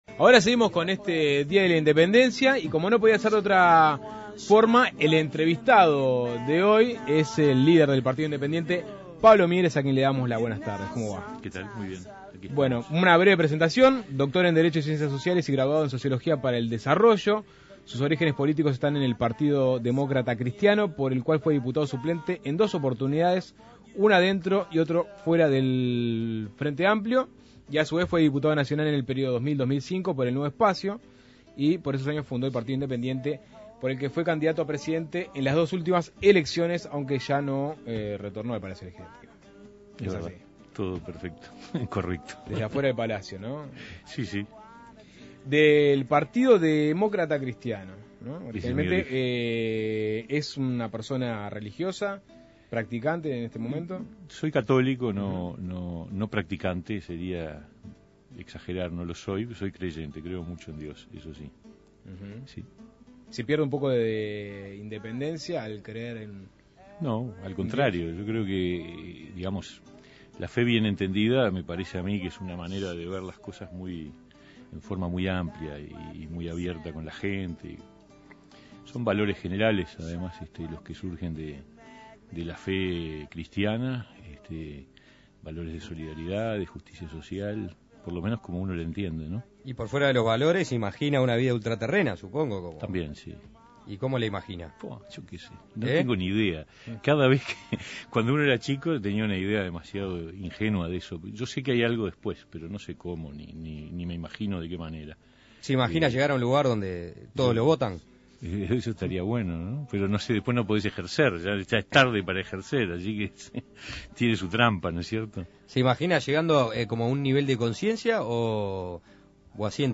Continuando con el formato independiente, el entrevistado no podía ser otro que el líder del Partido Independiente, Pablo Mieres, Doctor en Derecho y Ciencias Sociales y Graduado en Sociología para el Desarrollo, quien se ha erigido en representante de una minoría política que no se siente identificada en los intereses de los partidos tradicionales ni del Frente Amplio.